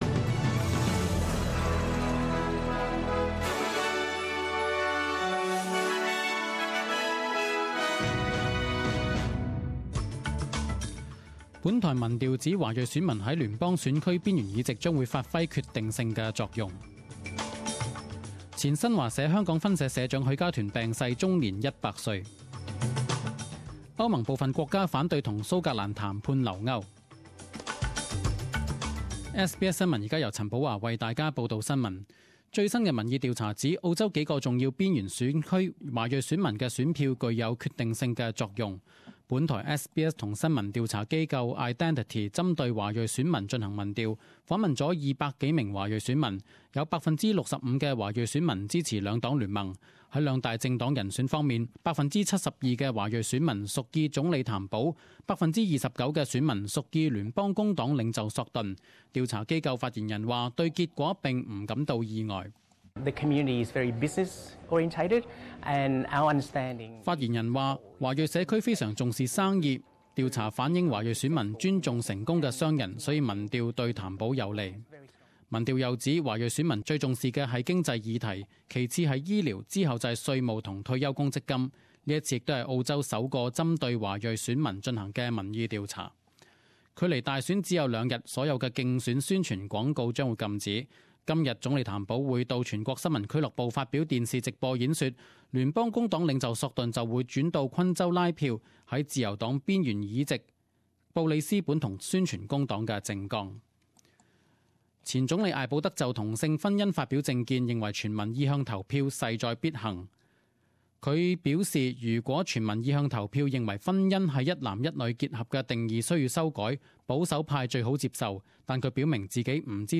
十点钟新闻报导（六月三十日）